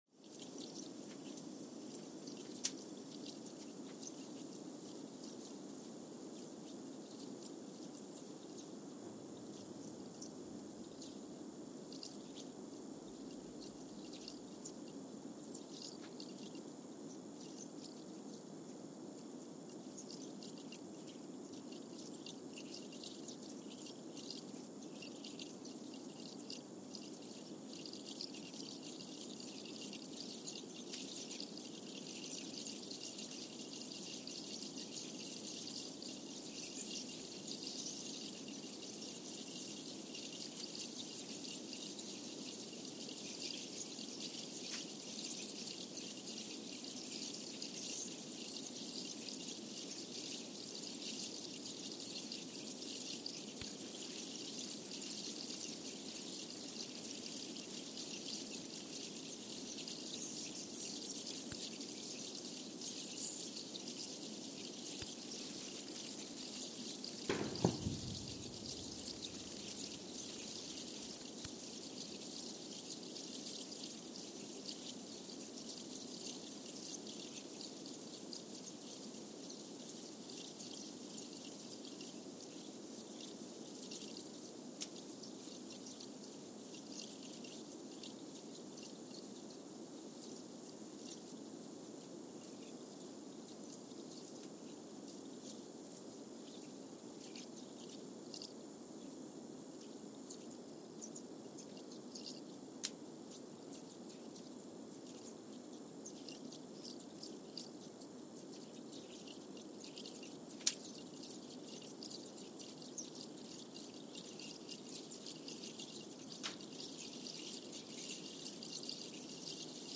San Juan, Puerto Rico (seismic) archived on January 27, 2023
Sensor : Trillium 360
Speedup : ×1,000 (transposed up about 10 octaves)
Loop duration (audio) : 05:45 (stereo)
SoX post-processing : highpass -2 90 highpass -2 90